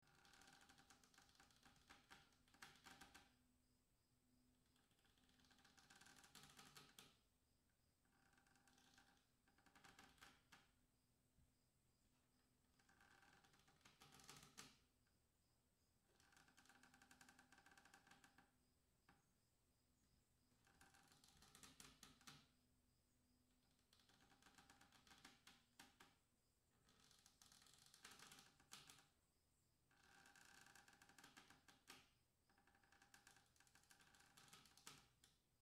Звуки рвущейся веревки или каната, натягивание и связывания для монтажа видео в mp3
5. Потрескивание натянутой веревки (тихое)